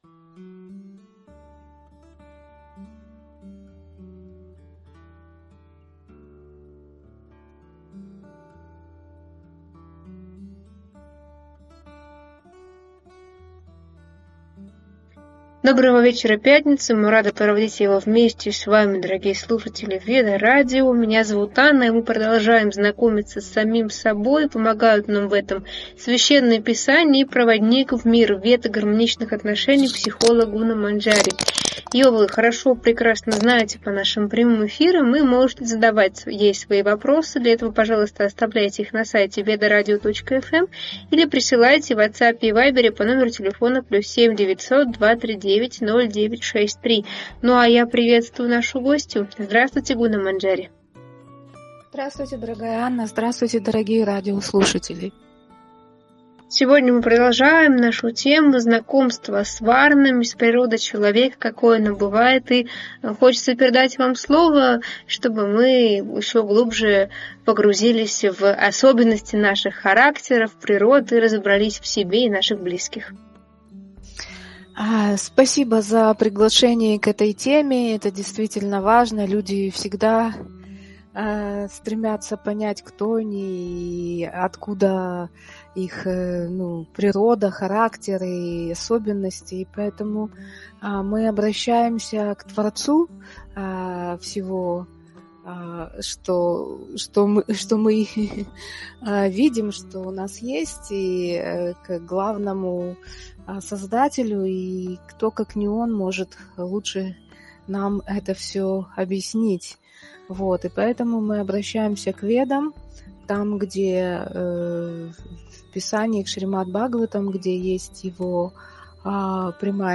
В эфире обсуждаются важные аспекты ролей брахманов и кшатрий в обществе, их влияние на жизненную энергию людей и психофизическую конституцию. Брахманы, обладающие мудростью и внутренней свободой, помогают обществу через знание и духовный рост, несмотря на деградацию в Кали-югу.